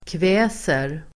Ladda ner uttalet
kväsa verb, put down , humble Grammatikkommentar: A & B/x Uttal: [kv'ä:ser] Böjningar: kväste, kväst, kväs, kväsa, kväser Synonymer: kuva Definition: trycka ned Exempel: kväsa ett uppror (put down an uprising)